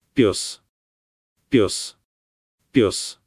Capitals for stress, "O" like in "poll", "A" like "u" in "sun", "E" like in "bell"
MALE DOG - PYOS (PYOS), ПЁС